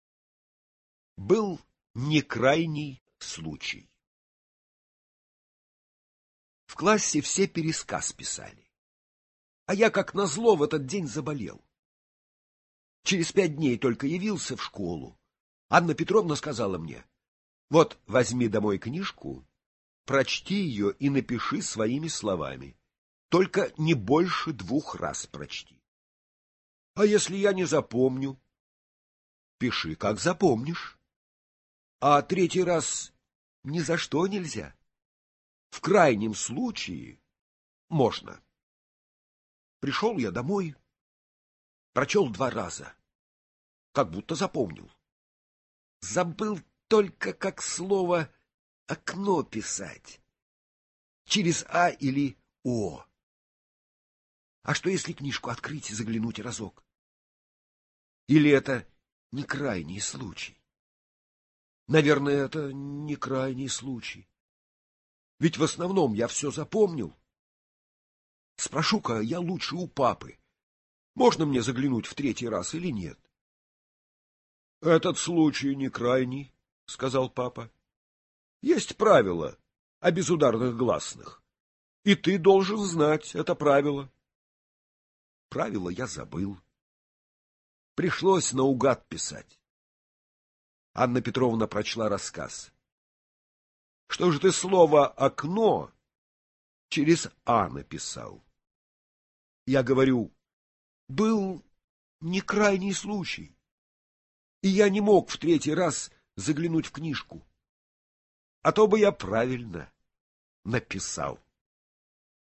Аудиорассказ «Был не крайний случай»